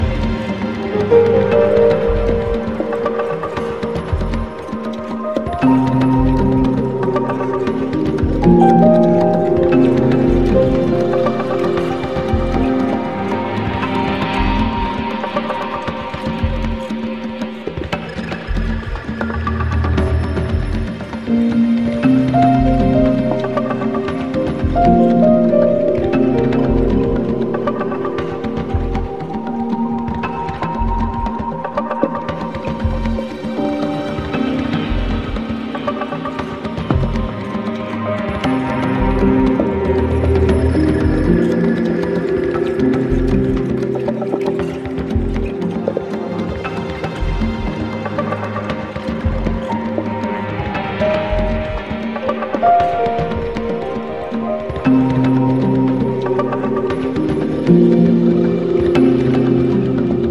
壮大なサウンドスケープは、忙しない日常の心の支えとなるメディテーションに没入できます。